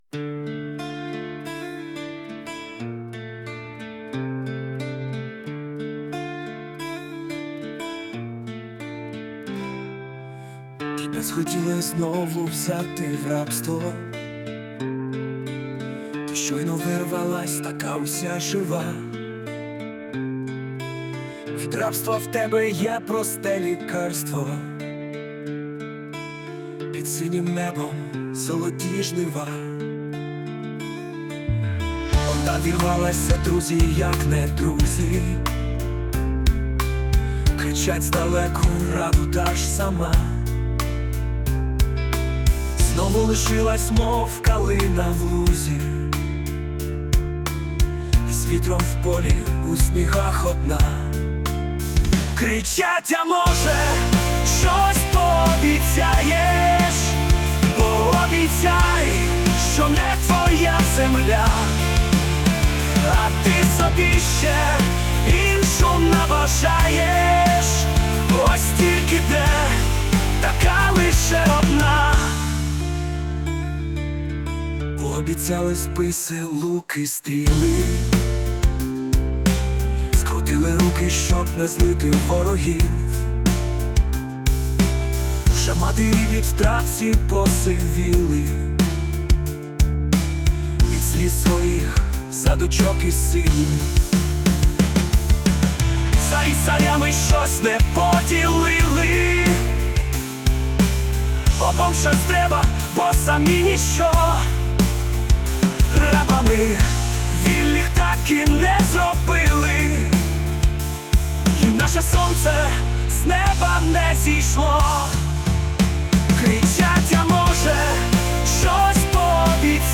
Музичний супровід створено з допомогою ШІ
СТИЛЬОВІ ЖАНРИ: Ліричний